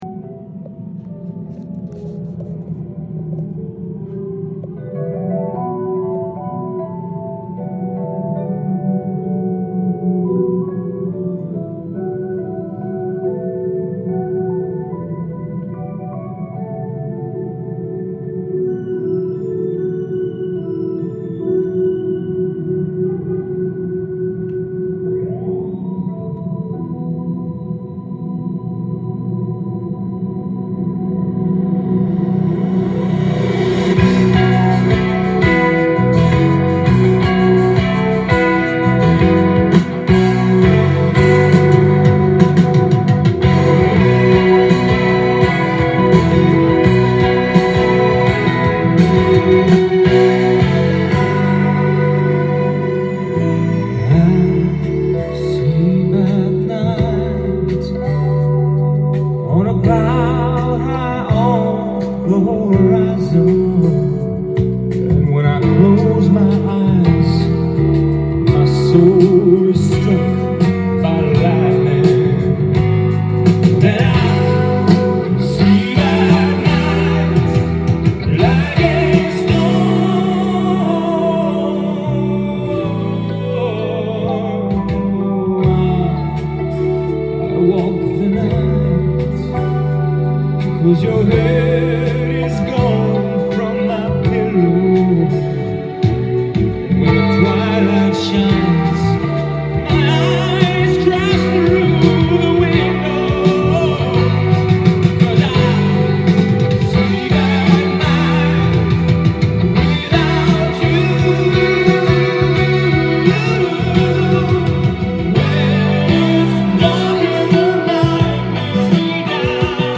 LP
店頭で録音した音源の為、多少の外部音や音質の悪さはございますが、サンプルとしてご視聴ください。